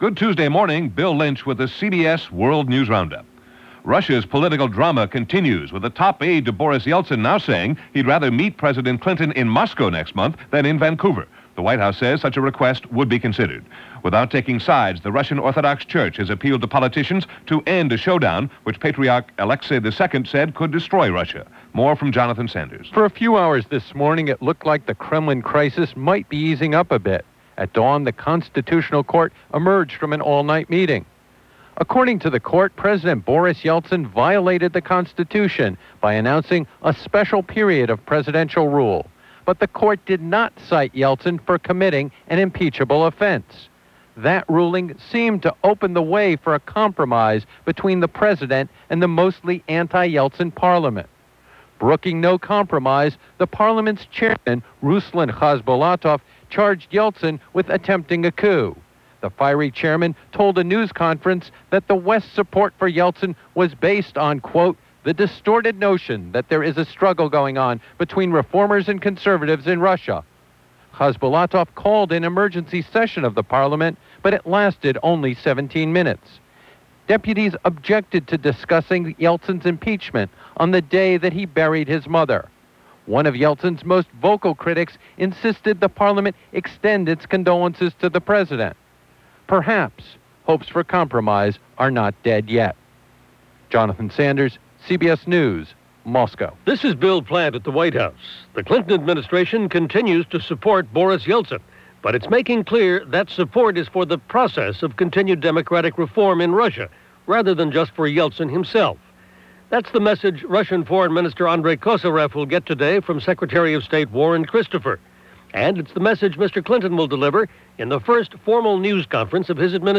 All that, and much-much more for this March 23, 1993 as presented by the CBS World News Roundup.